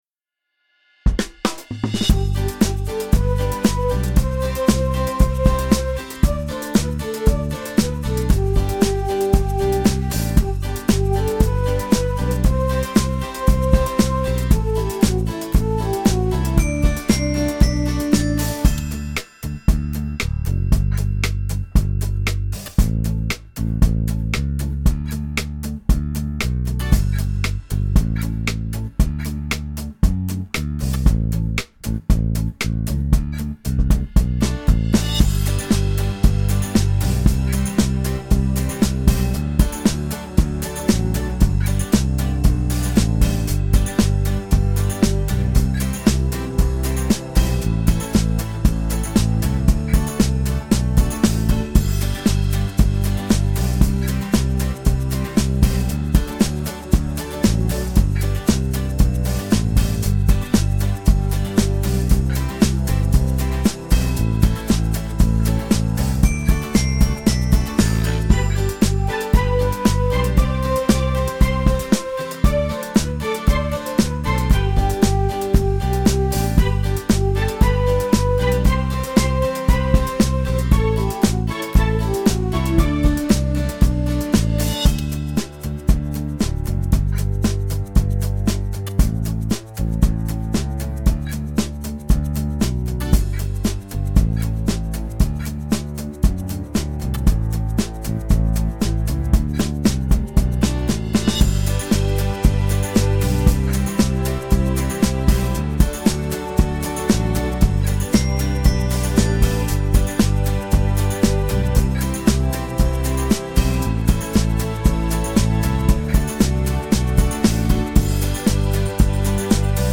Скачать минус детской песни